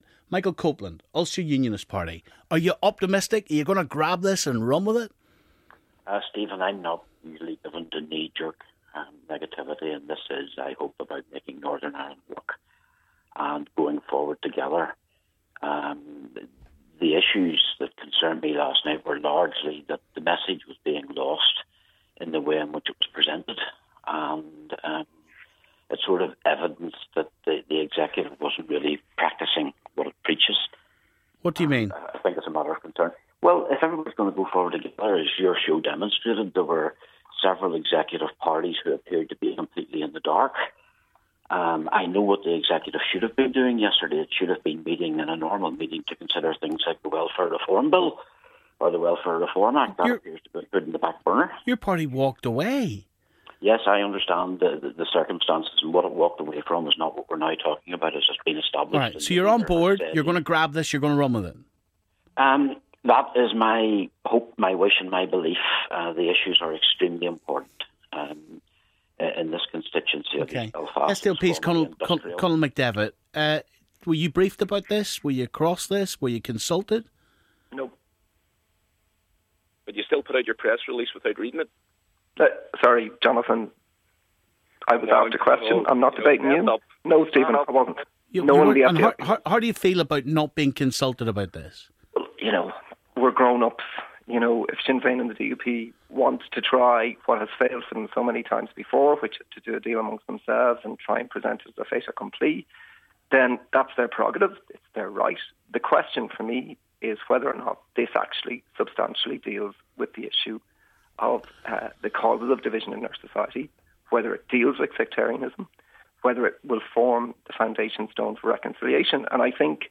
UUP's Michael Copeland and SDLP's Conal McDevitt join the discussion on the Shared Future proposals